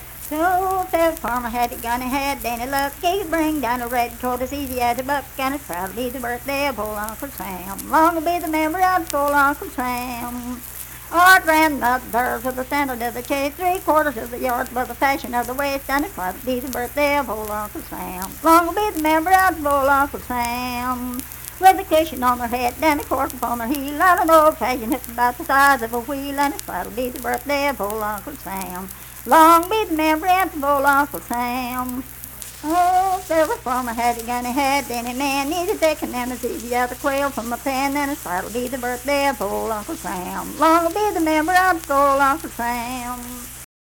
Unaccompanied vocal music performance
Political, National, and Historical Songs, War and Soldiers
Voice (sung)